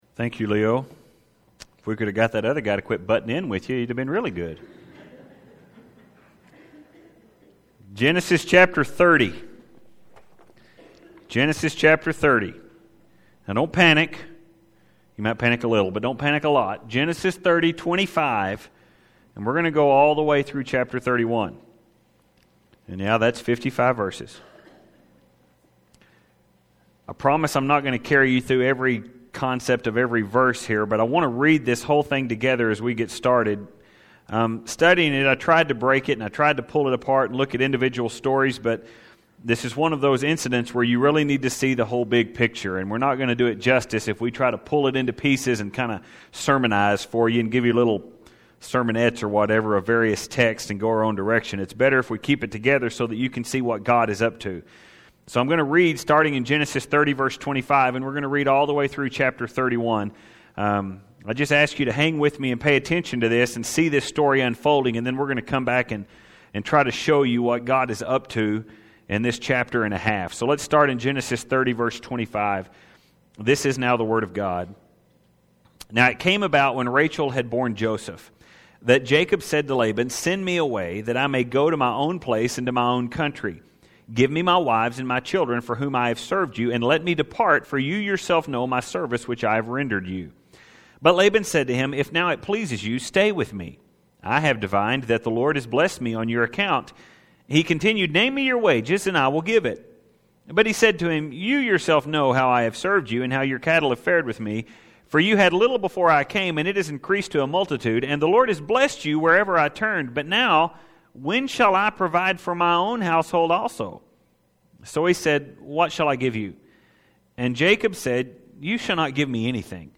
I realize this is a really long section of Scripture to cover in one sermon, and I promise I won’t walk you through every specific detail of the chapter.